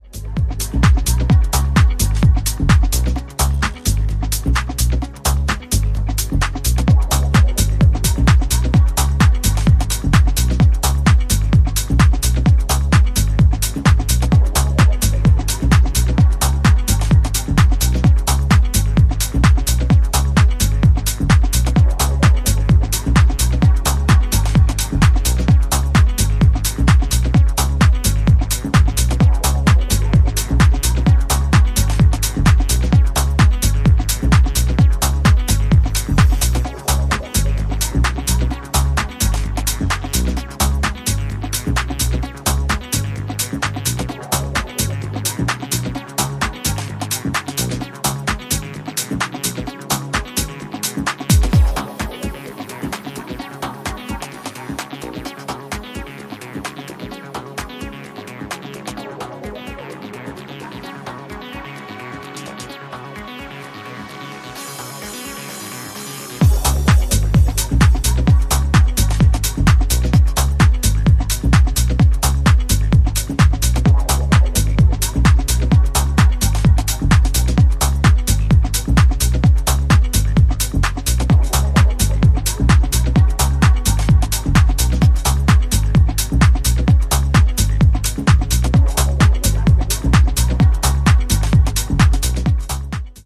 ジャンル(スタイル) TECH HOUSE / HOUSE